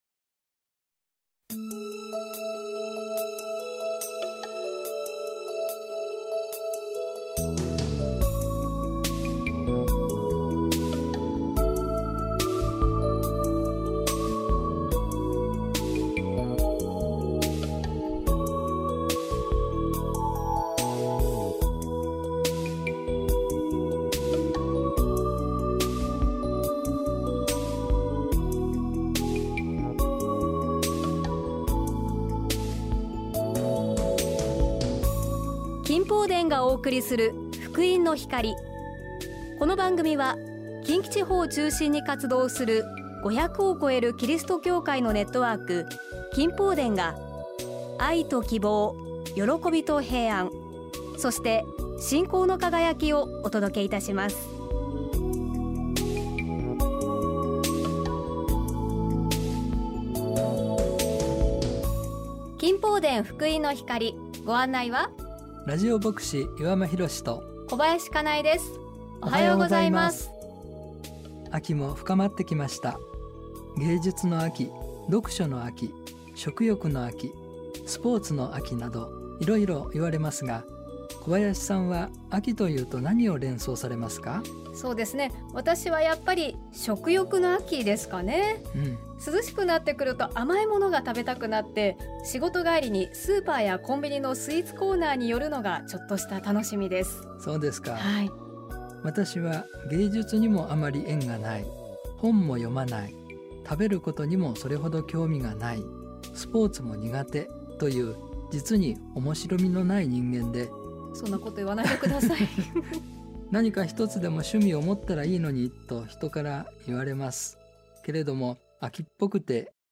単立 宝塚フェローシップ教会 御言葉とお話 「なぜここにいるのか？」